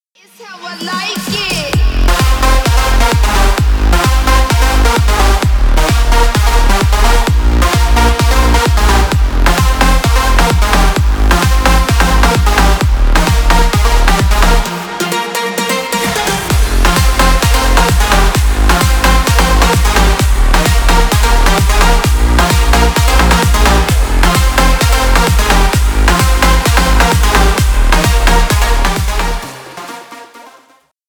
• Качество: 320 kbps, Stereo
Танцевальные
клубные